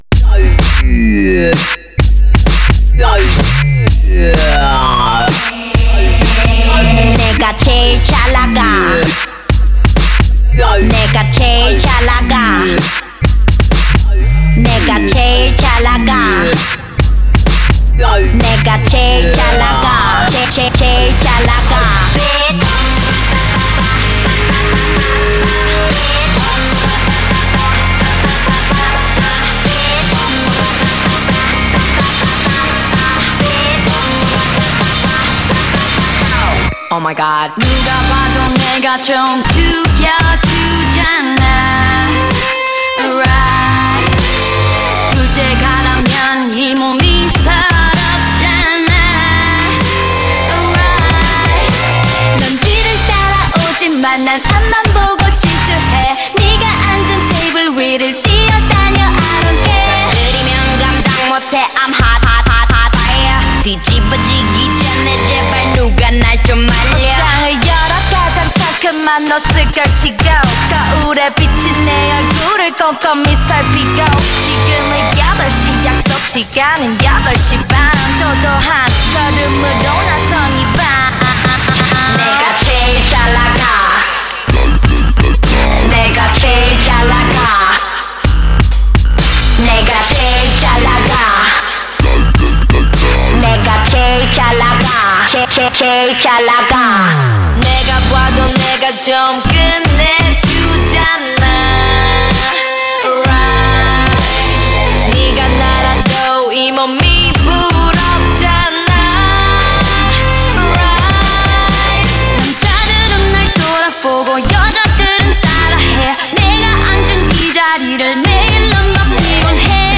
Genre(s): Dub-step